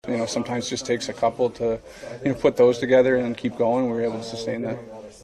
Sidney Crosby, who scored the game-winner after a slick transition off the bench in overtime, says the Pens knew they could win if they only got a break or two.